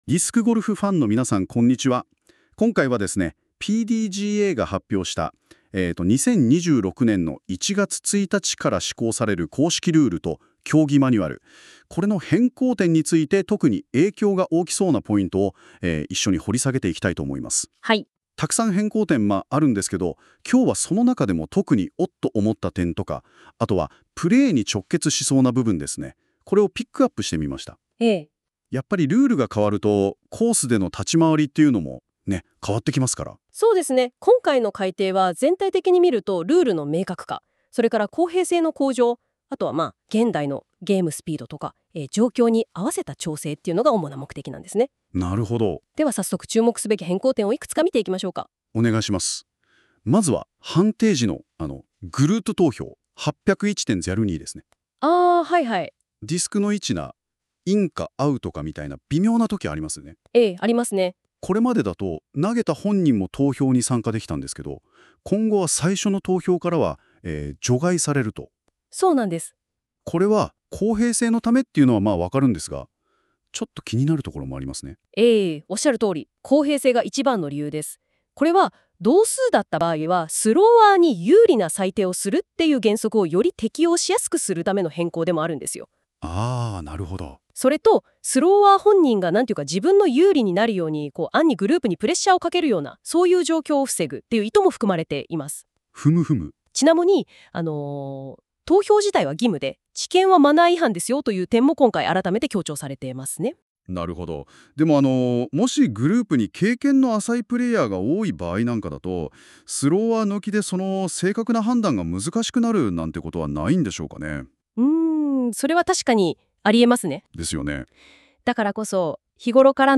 なお、本資料を作成するにあたっては、Google NotebookLMを利用しました。